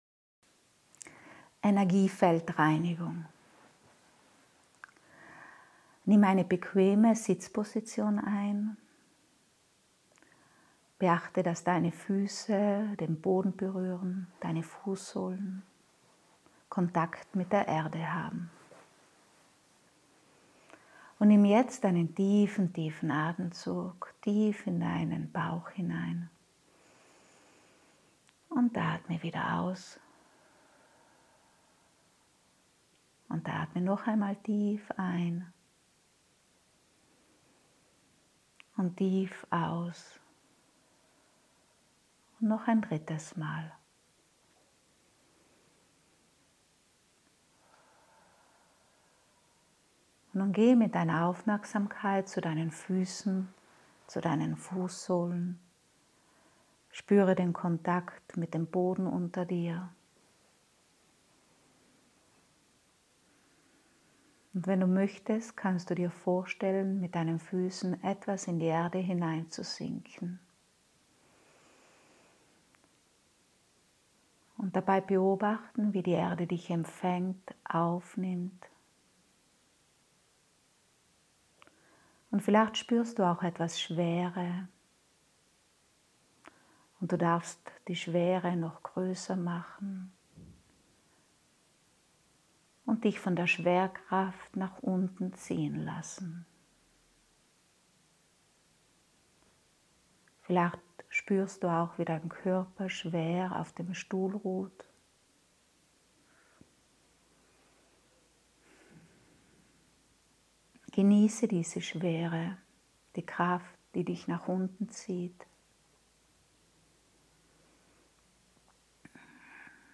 Meditation: Tägliche Energiefeldreinigung